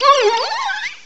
cry_not_lurantis.aif